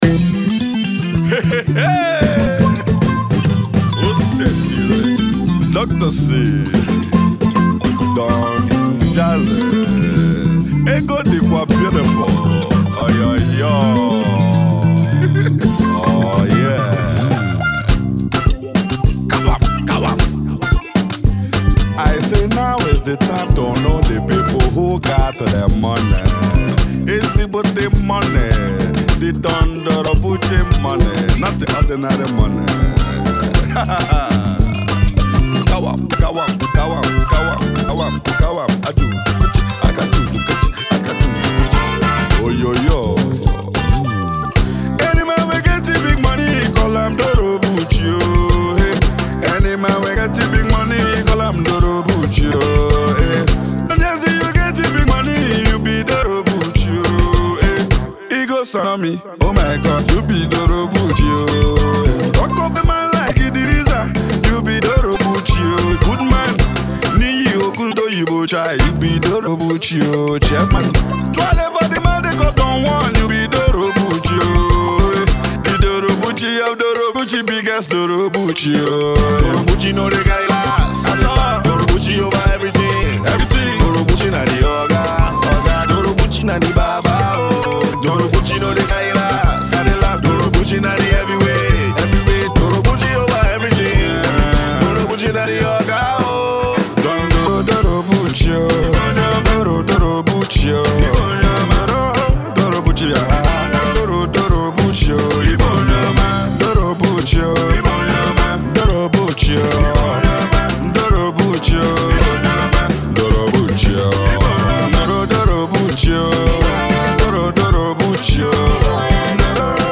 high life version